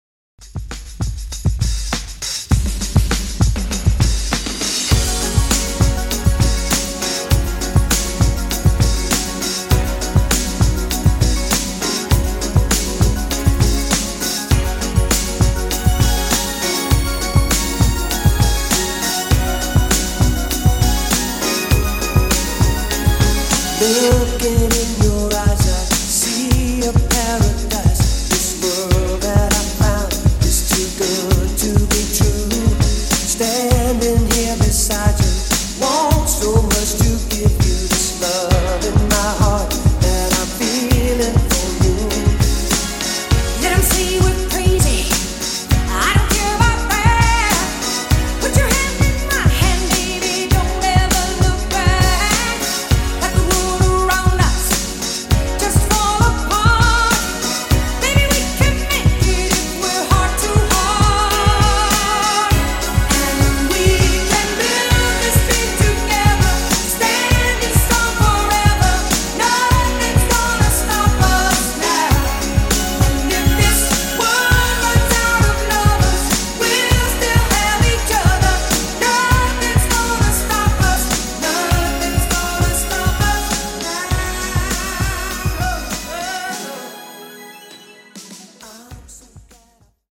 Classic Redrum)Date Added